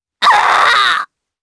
Nia-Vox_Dead_jp.wav